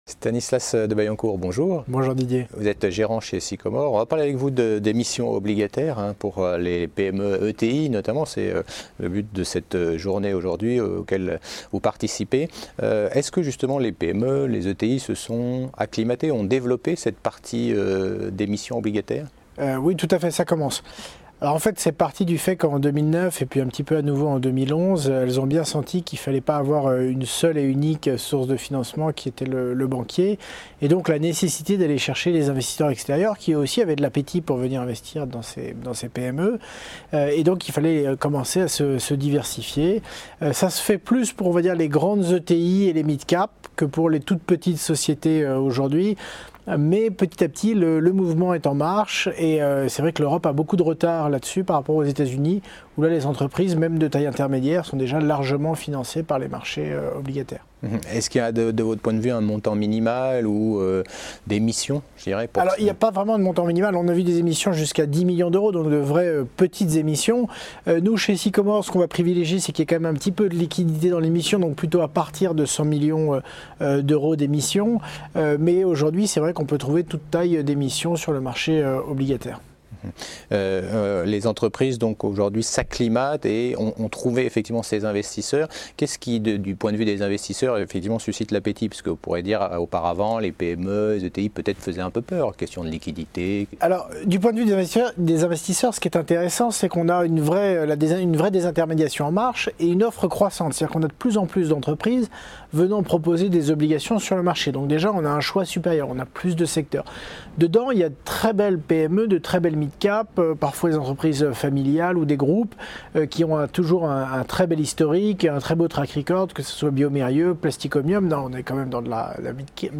European Fixed Income Event organisé par CF&B: L’émission obligataire, un nouveau mode de financement des PME – ETI